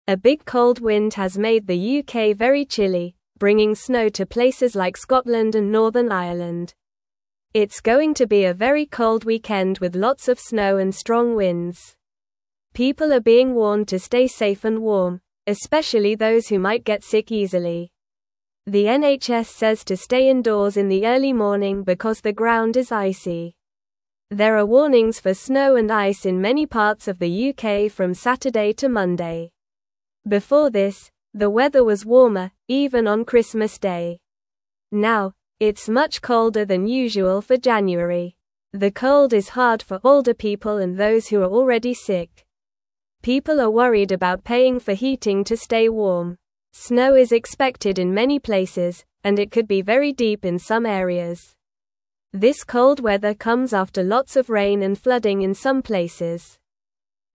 Normal
English-Newsroom-Beginner-NORMAL-Reading-Big-Cold-Wind-Brings-Snow-to-the-UK.mp3